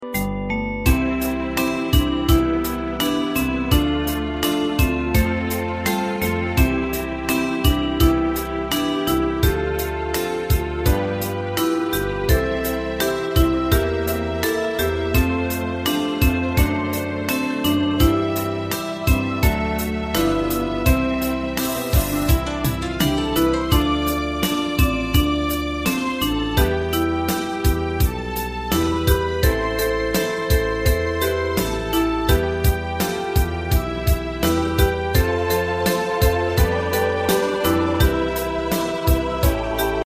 大正琴の「楽譜、練習用の音」データのセットをダウンロードで『すぐに』お届け！
カテゴリー: アンサンブル（合奏） .
日本のポピュラー